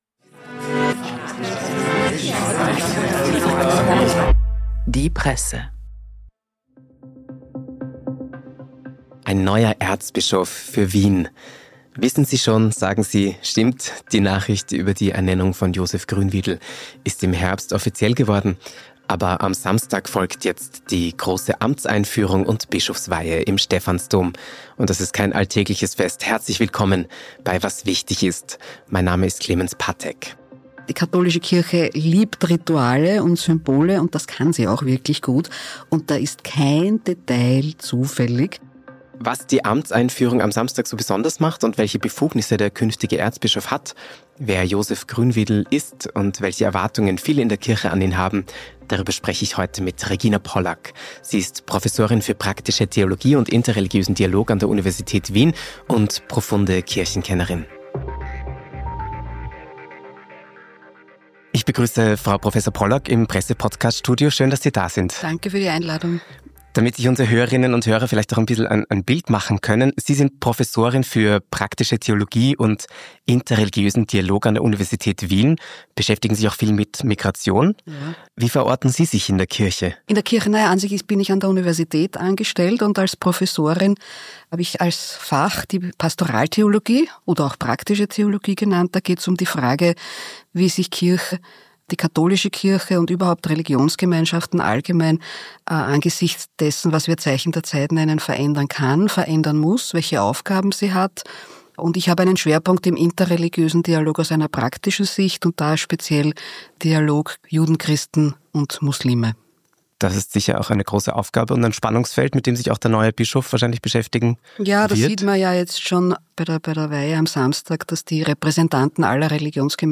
Wenn Josef Grünwidl am Samstag zum Bischof geweiht wird, wird der Stephansplatz zur Hochsicherheitszone. Die Erzdiözese Wien feiert ein großes Fest, steckt jedoch in einer veritablen Krise. Ein Gespräch